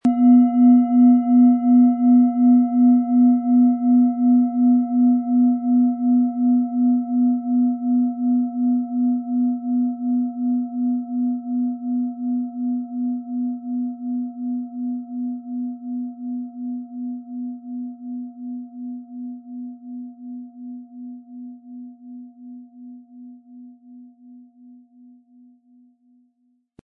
Planetenton 1
Wie klingt diese tibetische Klangschale mit dem Planetenton Lilith?
Durch die traditionsreiche Herstellung hat die Schale stattdessen diesen einmaligen Ton und das besondere, bewegende Schwingen der traditionellen Handarbeit.
MaterialBronze